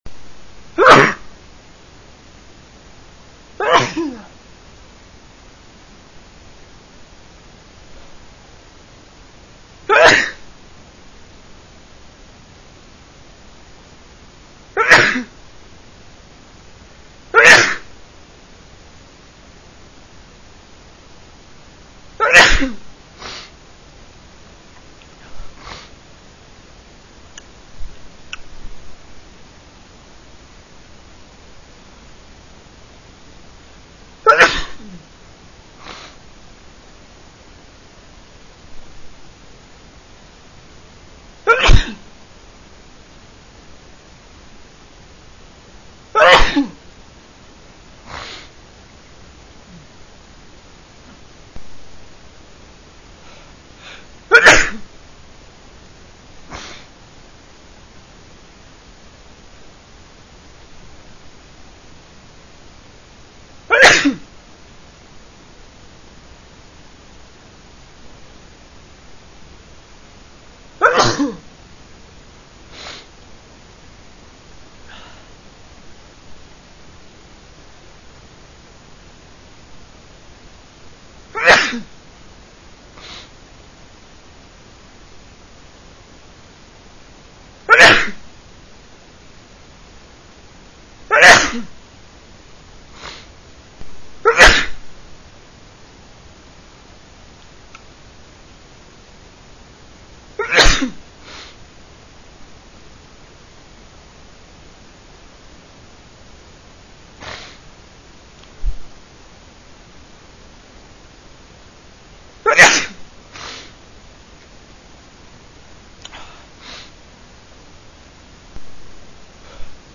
MALE WAVS
shower.wav